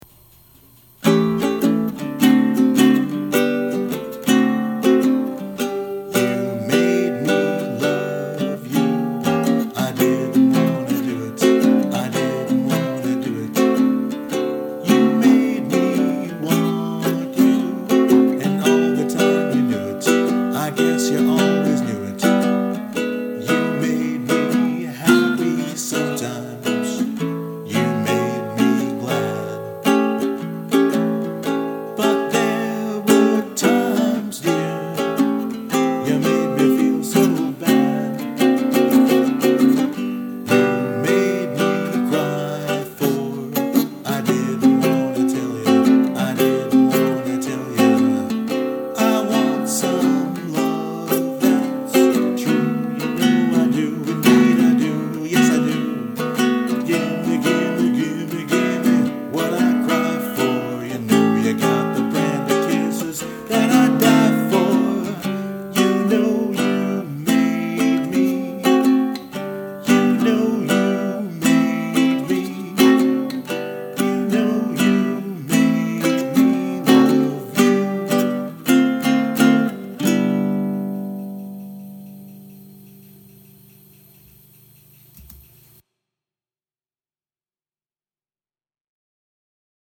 “You Made Me Love You” is another selection from the Great American Songbook; I seriously considered a Springsteen song, but I’m not sure we’d all have the patience to sit through my rendering of “Thunder Road.”
Filed under Music, Personal, Ukelele